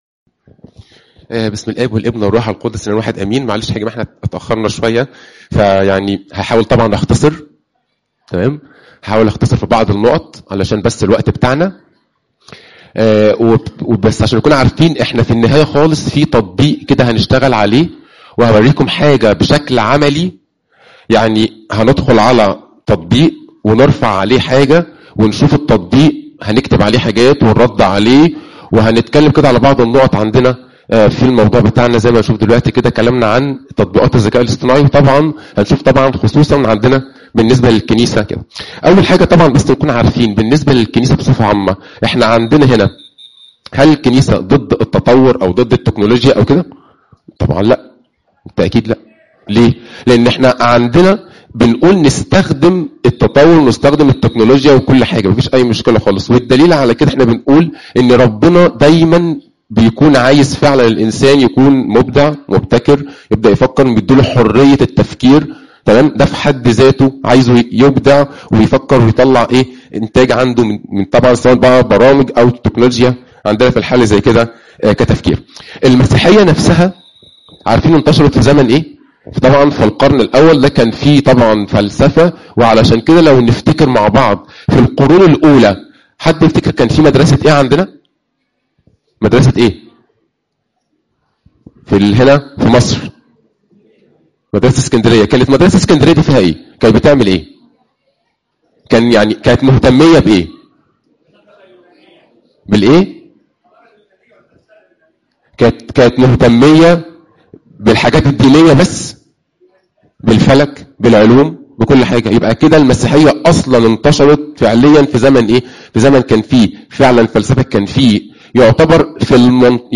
تفاصيل العظة
إجتماع الصخرة للشباب الخريجين